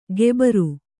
♪ gebaru